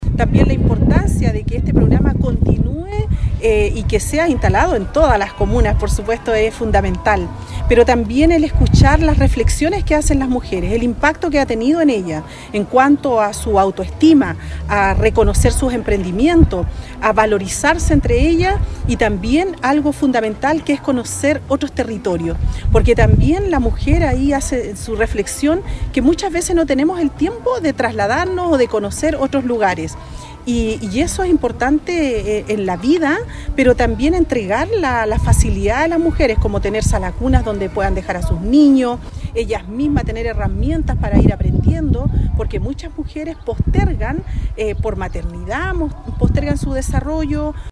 Hasta Pichasca, en la comuna de Río Hurtado, llegaron mujeres de Punitaqui, Combarbalá, Monte Patria y Ovalle, quienes formaron parte de un valioso encuentro provincial, impulsado desde el Servicio Nacional de la Mujer y la Equidad de Género (SernamEG) junto a los municipios ejecutores de la provincia.
Las participantes fueron recibidas por la alcaldesa Carmen Juana Olivares, quien destacó el encuentro y los relatos de las mujeres como muestra de valentía y propósito.